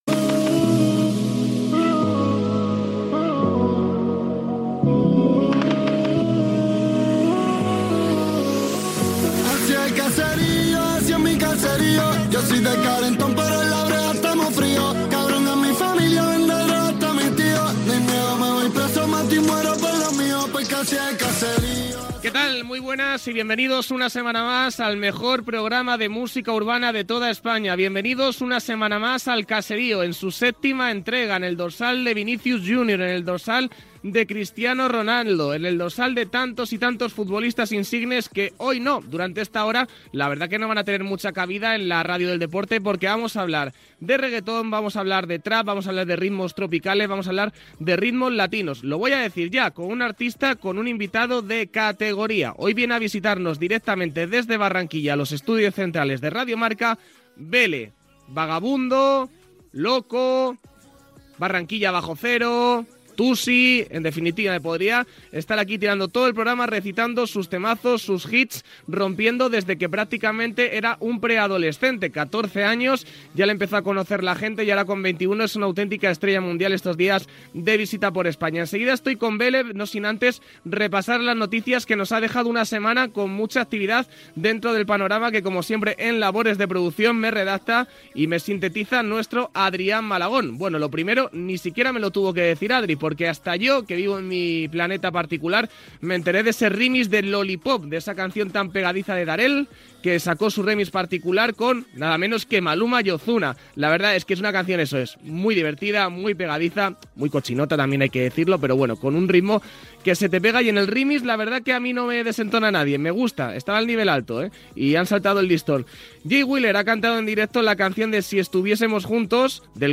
Careta, inici del programa, notícies musicals i fragment d'una entrevista al cantant Beéle Gènere radiofònic Musical